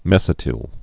(mĕsĭ-tĭl)